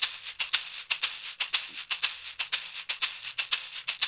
120 -UDU S0H.wav